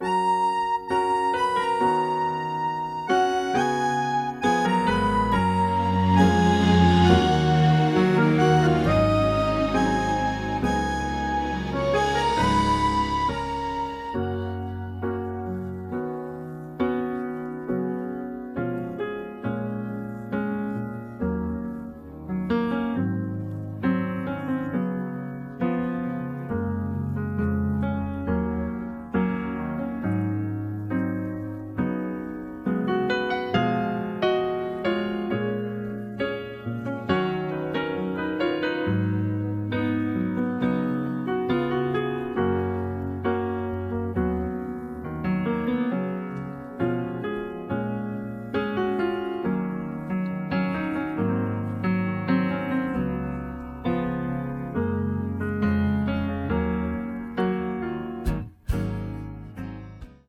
음정 -1키 4:27
장르 가요 구분 Voice Cut
Voice Cut MR은 원곡에서 메인보컬만 제거한 버전입니다.